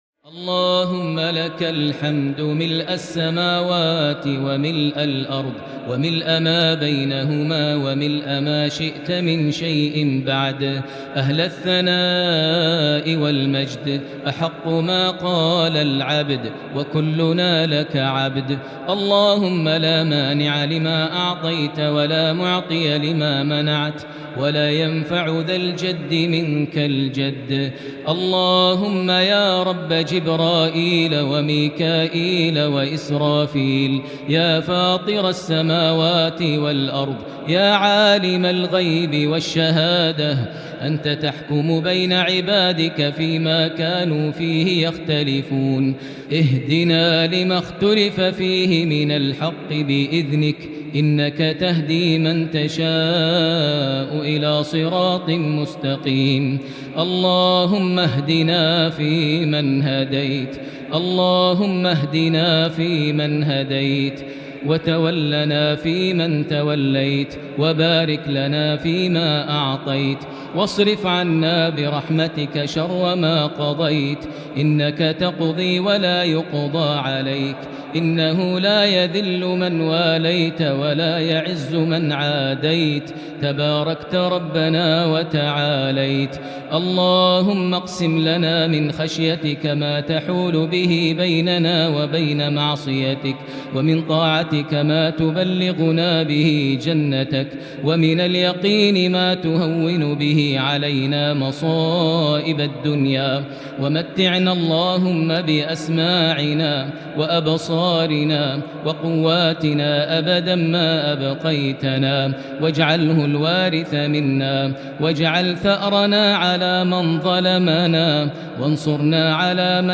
دعاء القنوت ليلة 24 رمضان 1441هـ > تراويح الحرم المكي عام 1441 🕋 > التراويح - تلاوات الحرمين